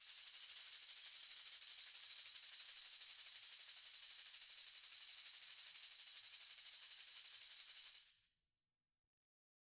Tamb1-Roll_v3_rr1_Sum.wav